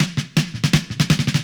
Grundge Fill.wav